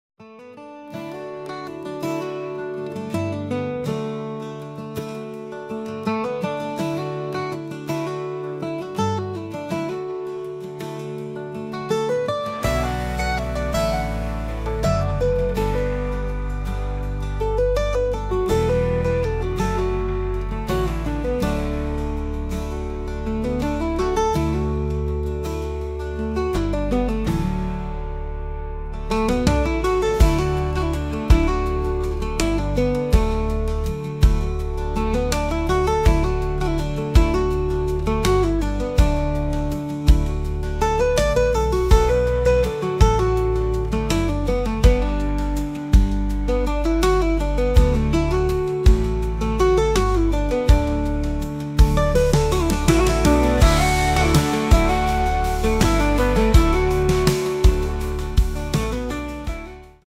Sanfte Countrymusik
Langsamer instrumental Sound mit Gitarrenklang.